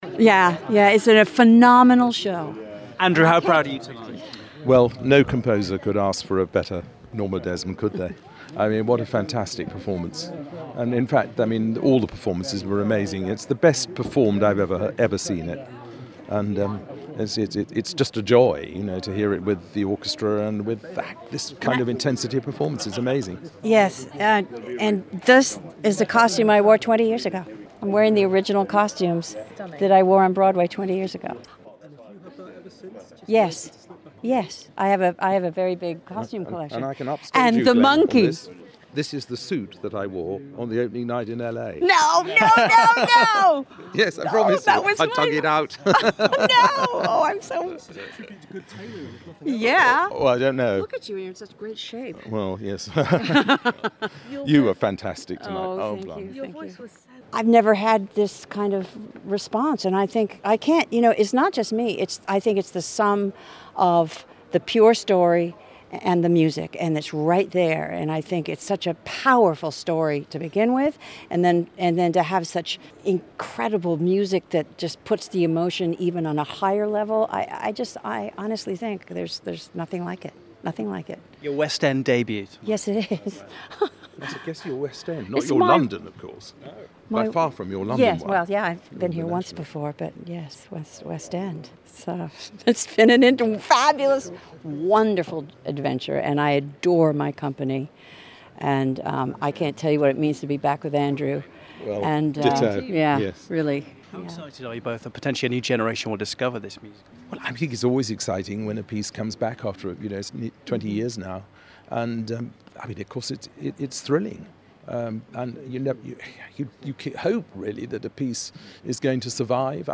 at London Coliseum Opening Night of Sunset Boulevard April 4th 2016